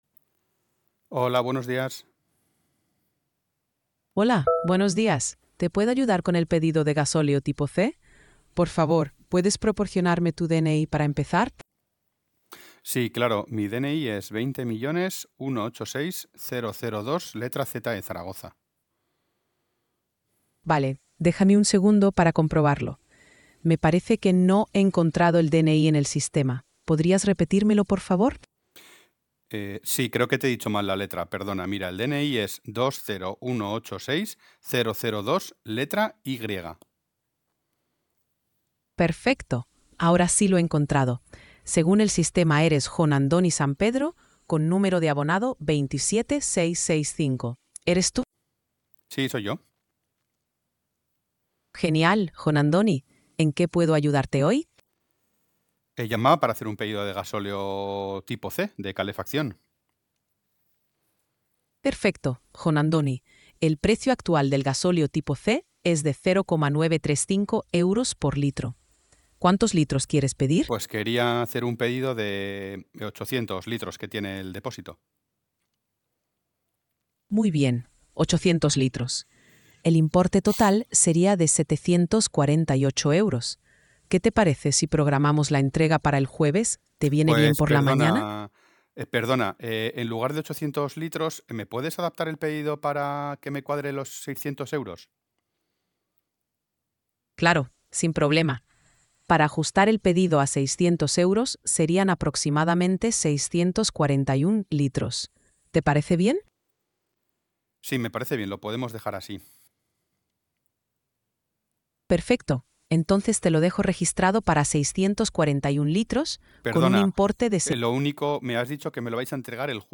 A continuación, puedes escuchar la conversación generada por IA en tiempo real durante nuestras pruebas:
Durante la prueba, simulamos una conversación de atención al cliente de 2 minutos y 47 segundos, generada en tiempo real mediante:
• Síntesis de voz (TTS) con tts-1-telephony
• Mantener una conversación fluida, sin pausas ni interrupciones artificiales.
• Confirmar datos finales y cerrar el ciclo de atención con tono cercano.